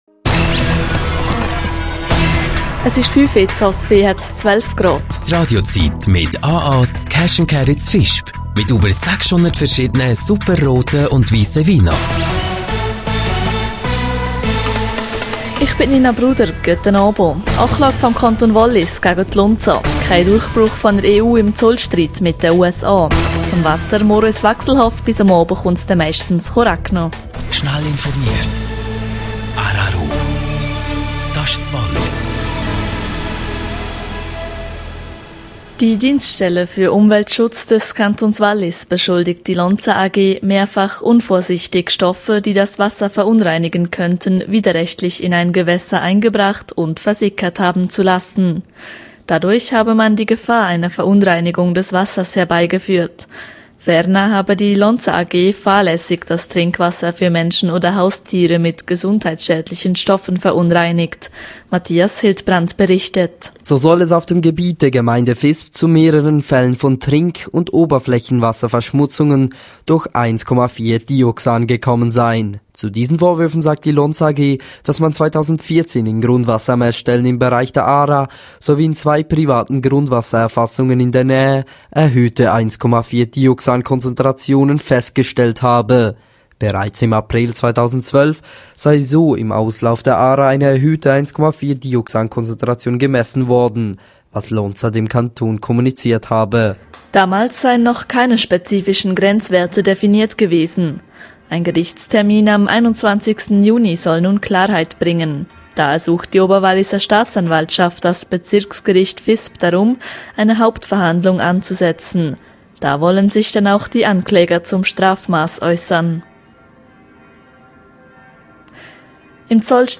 17:00 Uhr Nachrichten (4.32MB)